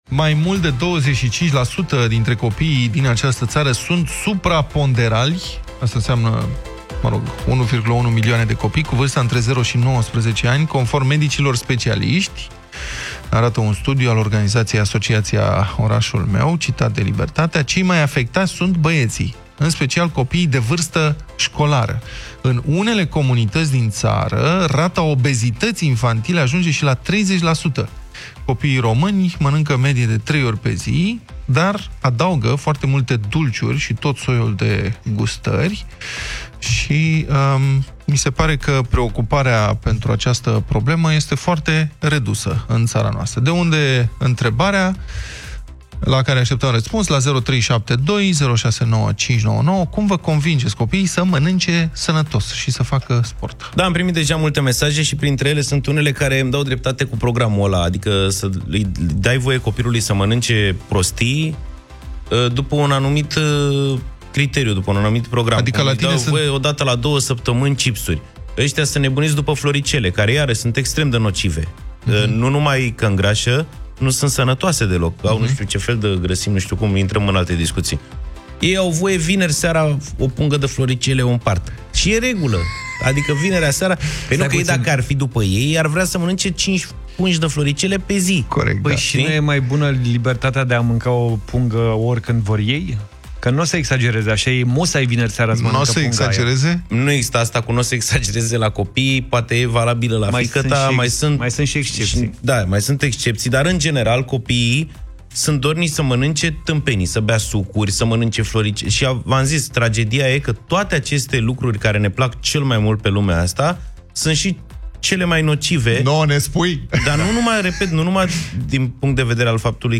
Puteți reasculta Deșteptarea din secțiunea podcast Europa FM, cu ajutorul aplicației gratuite pentru Android și IOS Europa FM sau direct în Spotify și iTunes.